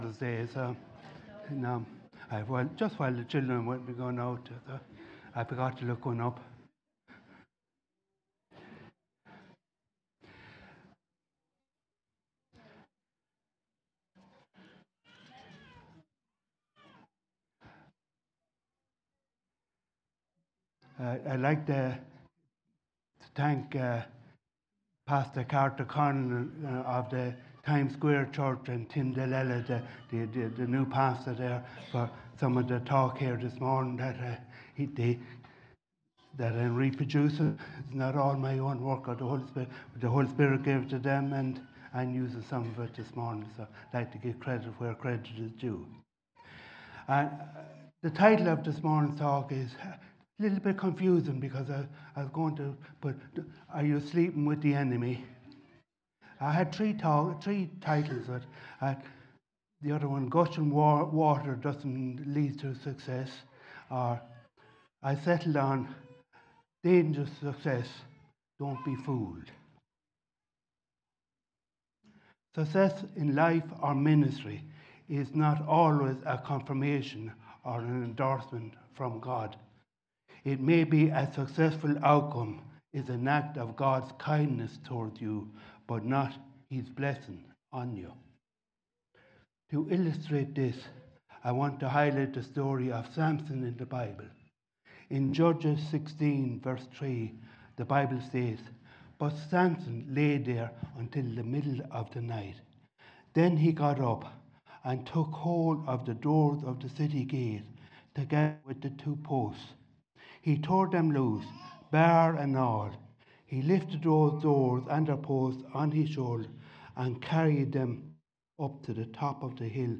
Sermons recorded during 2025